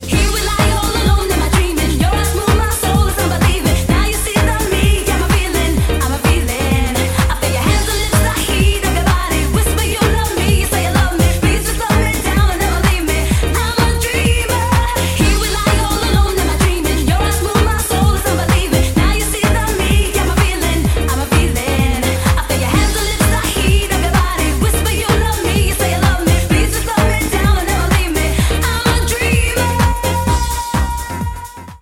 • Качество: 128, Stereo
евродэнс